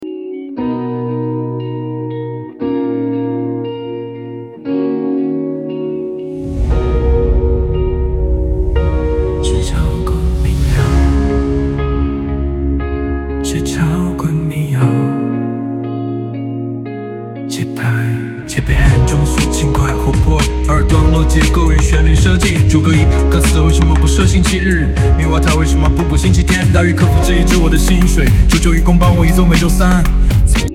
- 歌曲类型：轻摇滚民谣
- 调式：G大调
- 节拍：4/4拍
- 速度：110BPM（中速，轻快活泼）
人工智能生成式歌曲